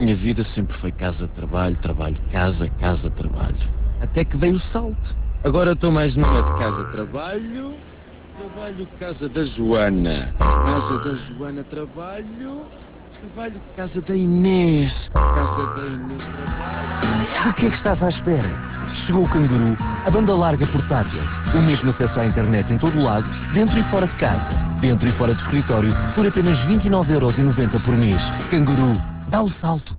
A Optimus lançou um novo serviço - Kanguru (a banda larga portátil) - cuja campanha de rádio estreou dia 17 de Setembro com um Teaser, que foi desvendado no dia 21. Esta campanha passa na Cidade FM, RFM, RC e Mega FM onde até ao dia 25 de Setembro foram investidos 71 875 euros, a preço tabela, por 361 inserções (